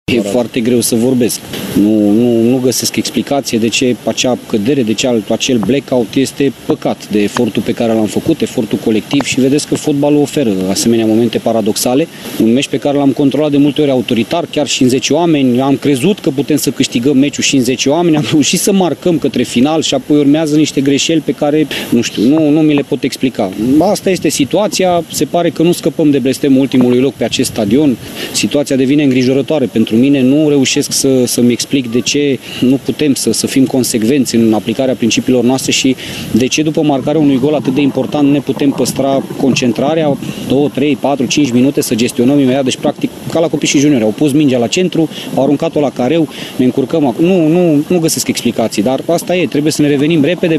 Antrenorul Balint Laszlo era stupefiat la final de partidă: